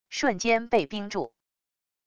瞬间被冰住wav音频